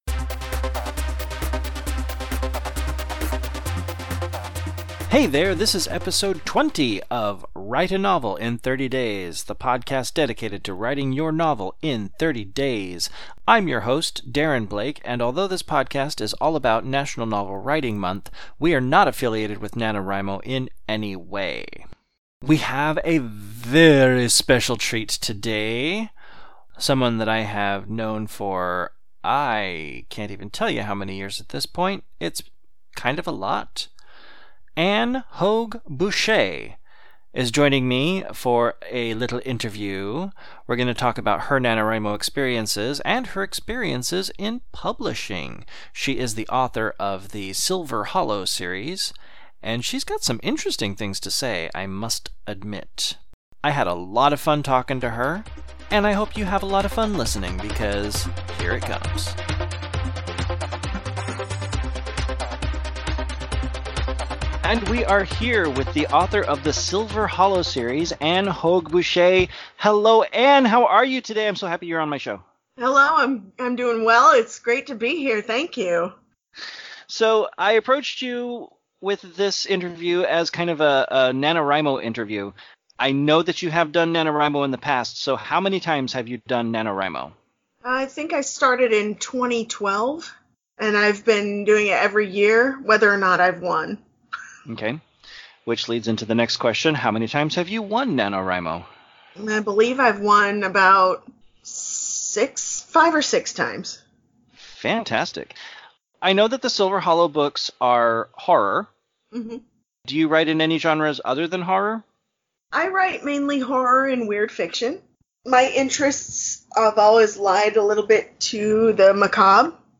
PODCAST #20: INTERVIEW